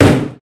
impact-1.ogg